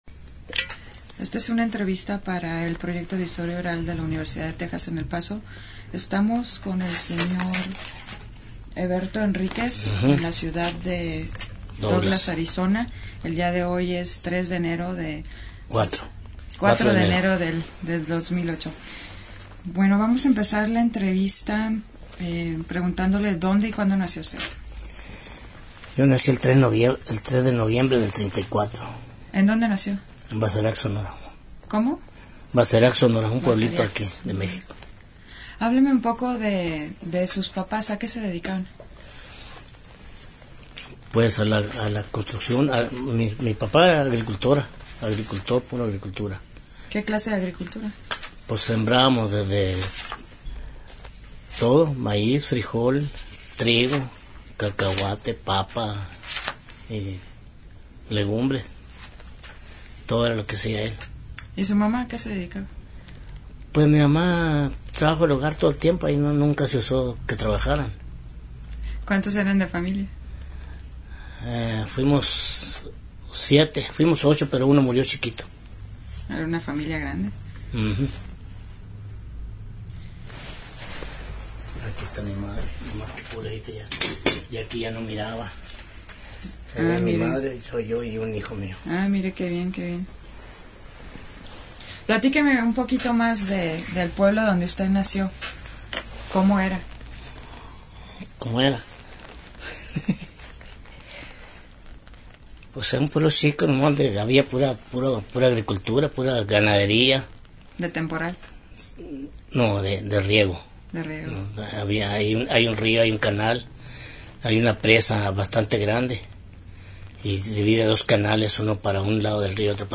Location Douglas, Arizona